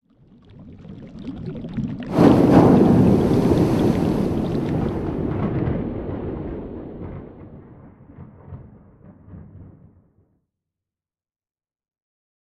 cauldron-done.ogg.mp3